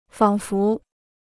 仿佛 (fǎng fú): to seem; as if.
仿佛.mp3